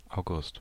Ääntäminen
Synonyymit (vanhahtava) Ernting (vanhahtava) Erntemonat (vanhahtava) Erntemond Ääntäminen month: IPA: /aʊ̯.ˈɡʊst/ given name: IPA: /aʊ̯.ˈɡʊst/ Lyhenteet ja supistumat Aug.